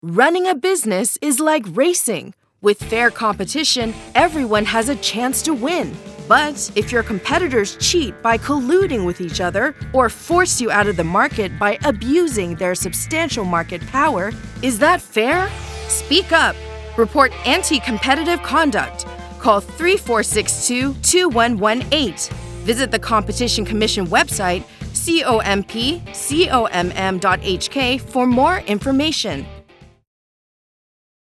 Radio Announcements